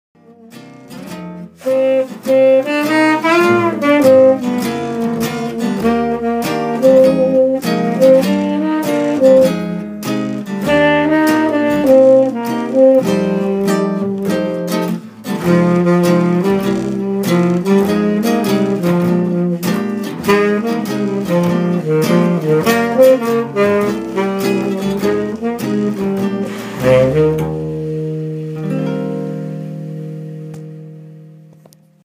Jazz & Swing